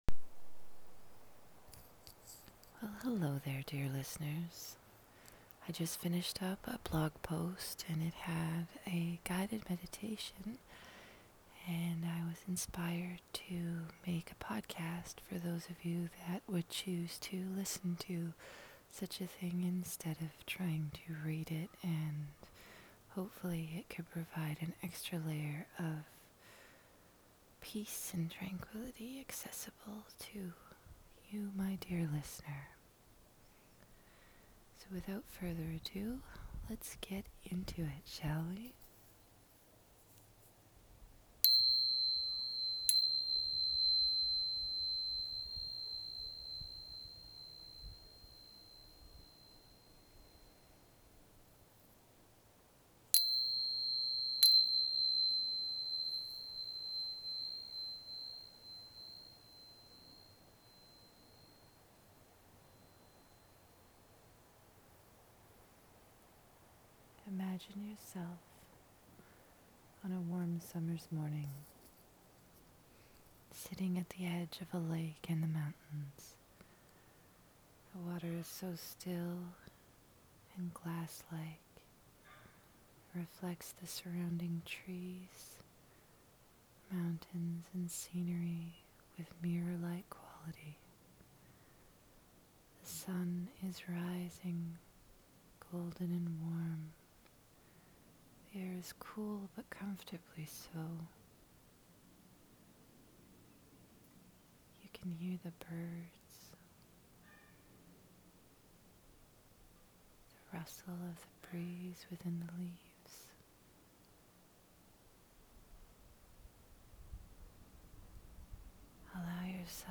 Todays post is a bit of a guided meditation.
meditation.m4a